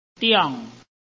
臺灣客語拼音學習網-客語聽讀拼-海陸腔-鼻尾韻
拼音查詢：【海陸腔】diong ~請點選不同聲調拼音聽聽看!(例字漢字部分屬參考性質)